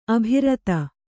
♪ abhirata